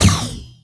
bounce_fire_01.wav